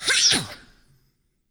48 RSS-VOX.wav